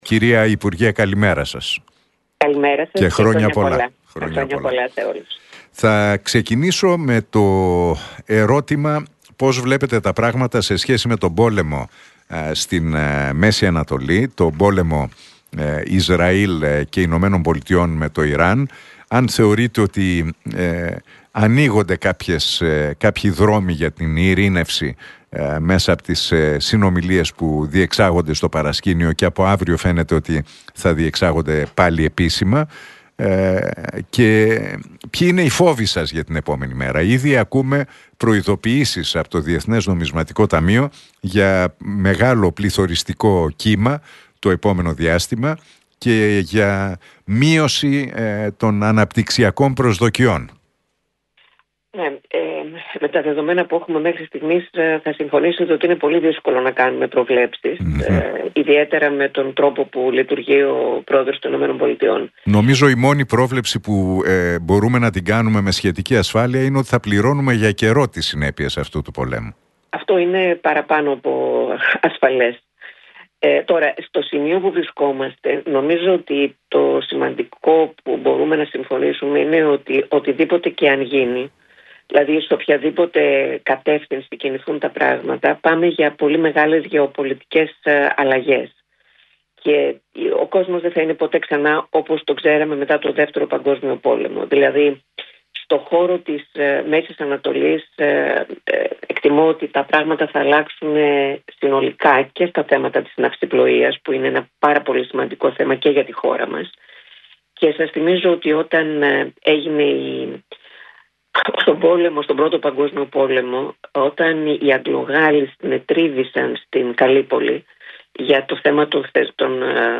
Για τις διεθνείς και εσωτερικές εξελίξεις μίλησε η Άννα Διαμαντοπούλου στον Realfm 97,8 και την εκπομπή του Νίκου Χατζηνικολάου.